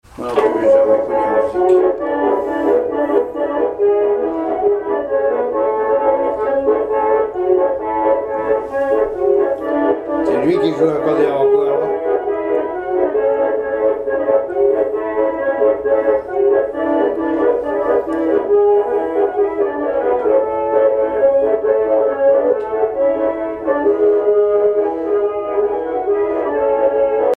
accordéon touches piano
danse : marche
Pièce musicale inédite